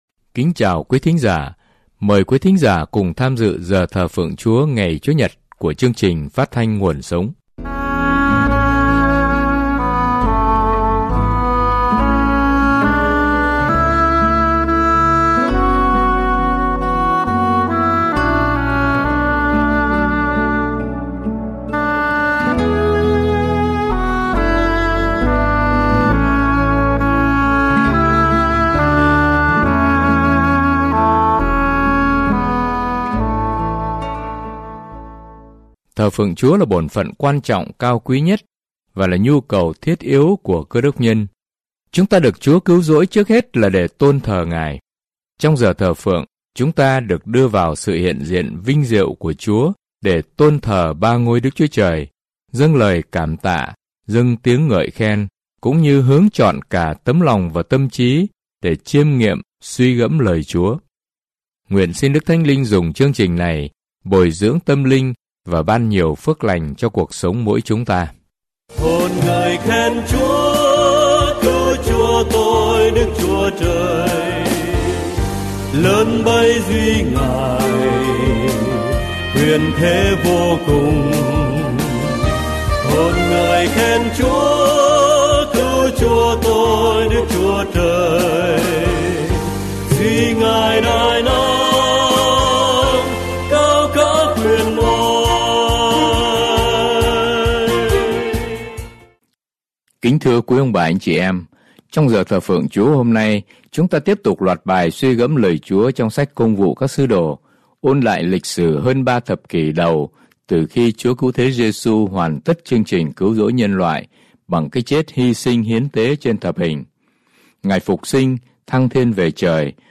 Thờ Phượng Giảng Luận